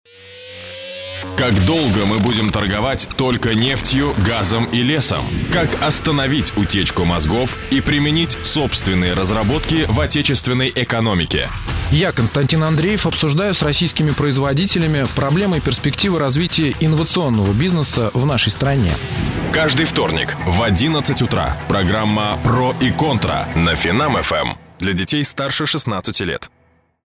Тракт: продакшн студия радиостанции. продакшн студия рекламного агентства домашний продакшн mic AKG, audio - Lexicon, Sony SF, Cubase